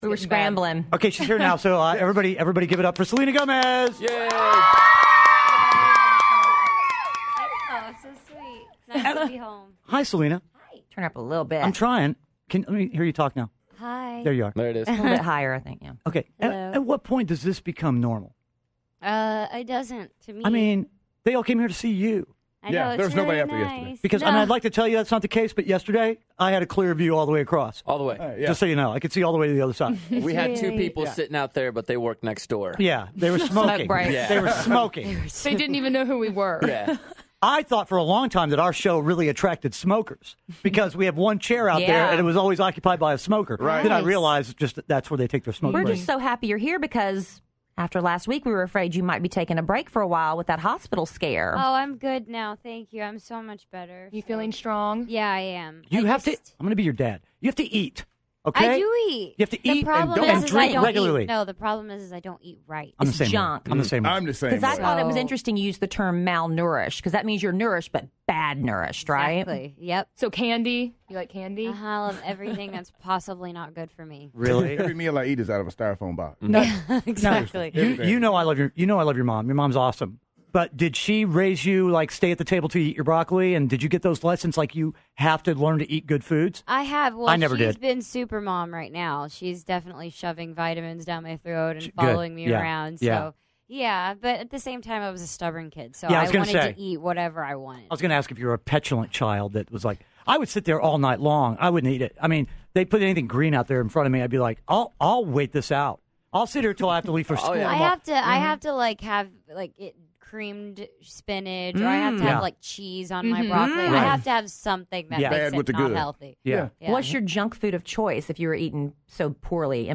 Kidd Kraddick in the Morning interviews Selena Gomez!